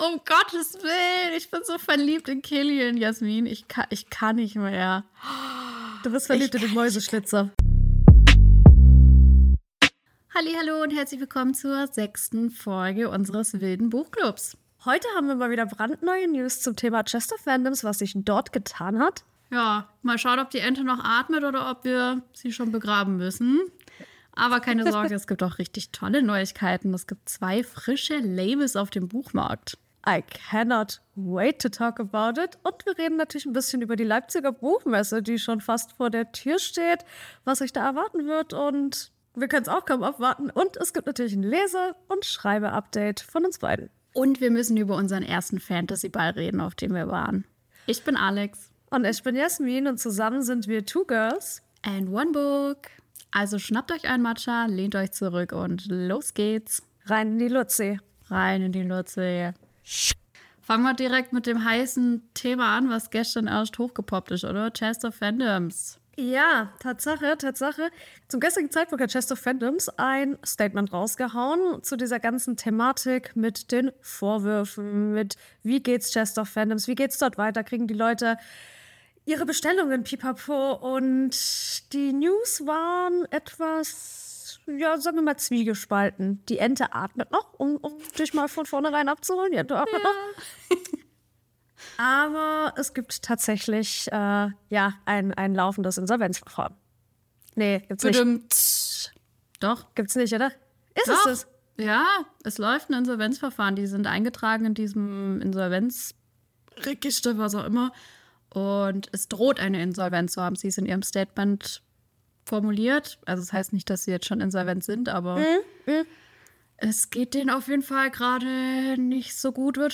📚🎙 Wir sind laut, frech und ehrlich – …und wenn Booktok eine Cocktailparty wäre, wären wir der Tisch mit den wildesten Gesprächen. Hier geht’s um mehr als nur Bücher: Wir reden über Hypes, Tropes und die Abgründe des Schreibens, und das immer mit einer gehörigen Portion Humor.